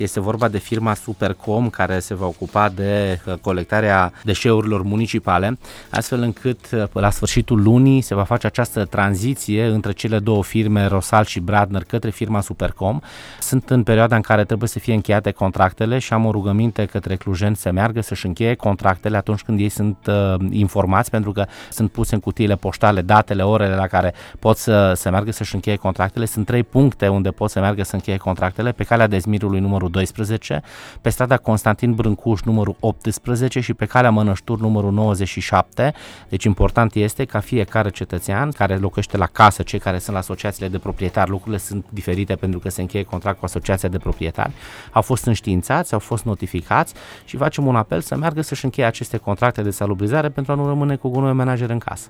Explicațiile au venit de la viceprimarul municipiului Cluj-Napoca, Dan Tarcea, invitat marți, 10 ianuarie 2023, la postul nostru de radio: